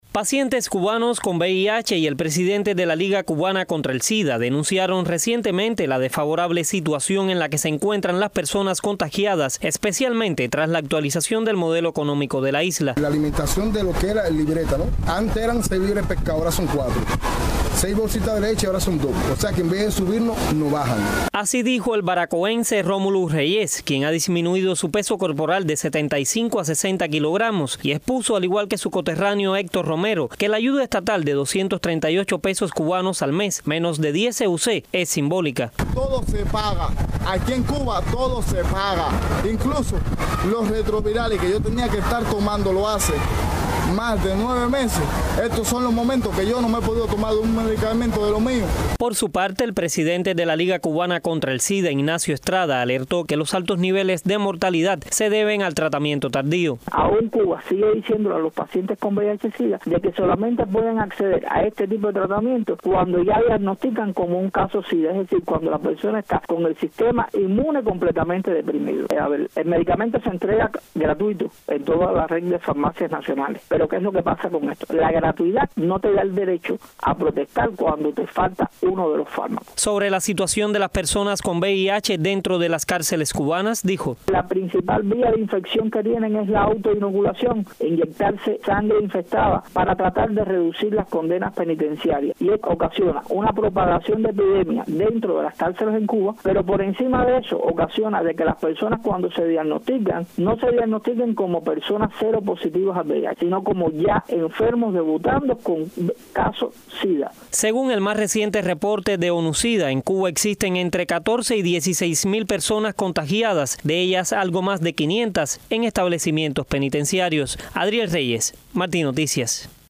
el siguiente informe